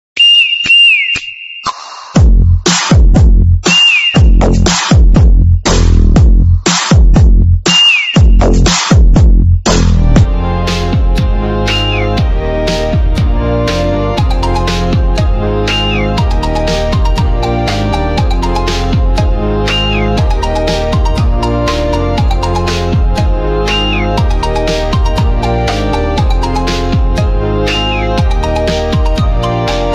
Mandopop Pop
2025-07-07 Жанр: Поп музыка Длительность